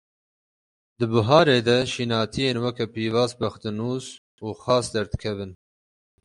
Pronunciato come (IPA)
/xɑːs/